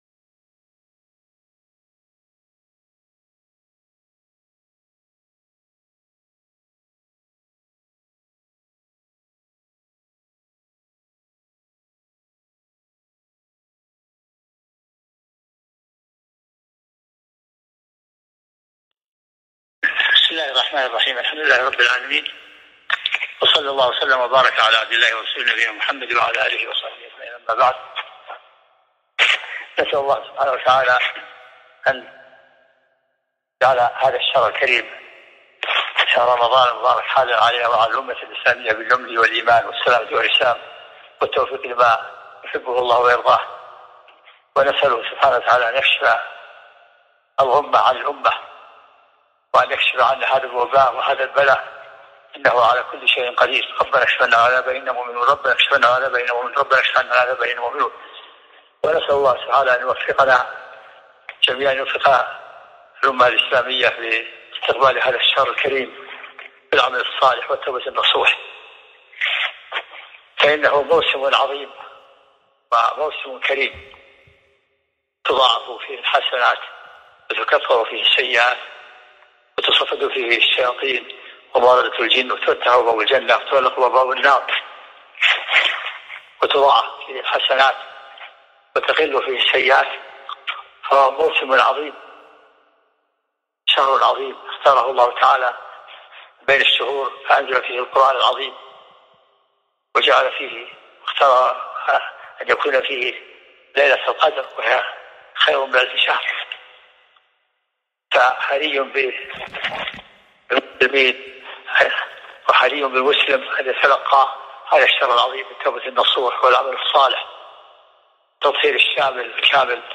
وصايا لمن ادركه رمضان عبر البث المباشر 1441هــ